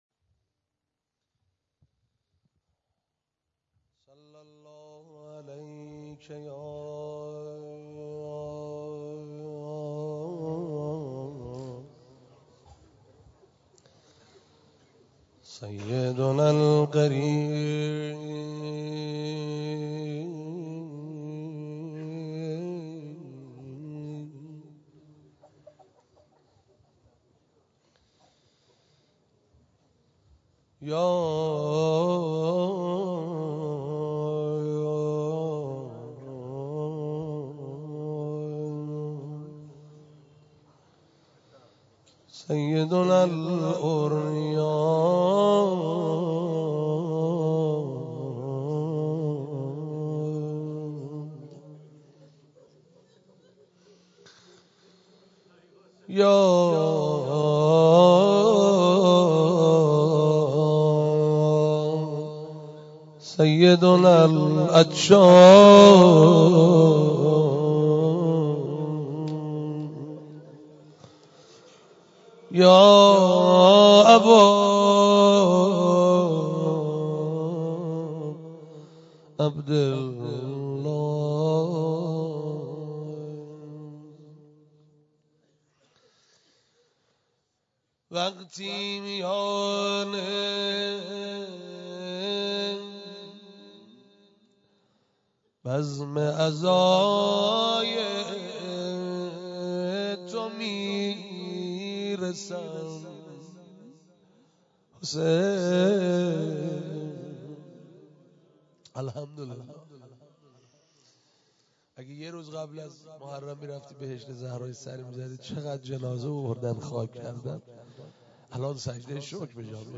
روضه شب دوم
شب دوم محرم96